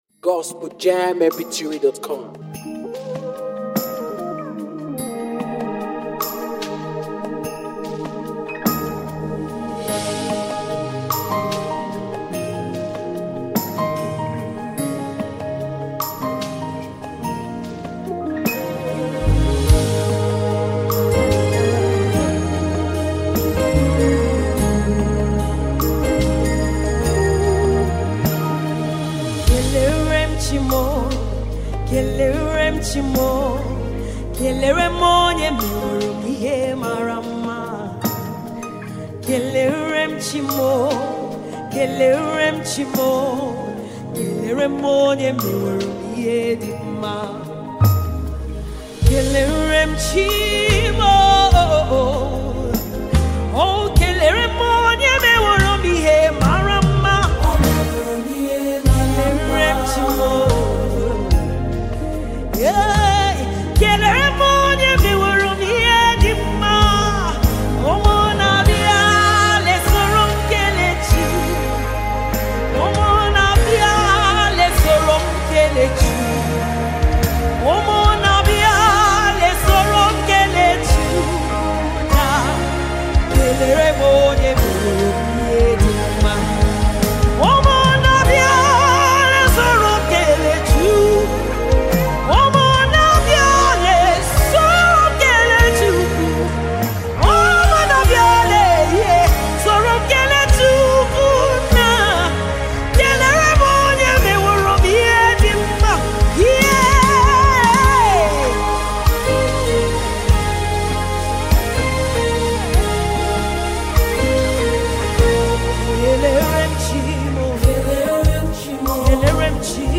NUMBER 1 AFRICA GOSPEL PROMOTING MEDIA